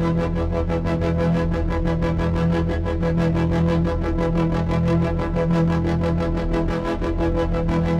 Index of /musicradar/dystopian-drone-samples/Tempo Loops/90bpm
DD_TempoDroneA_90-E.wav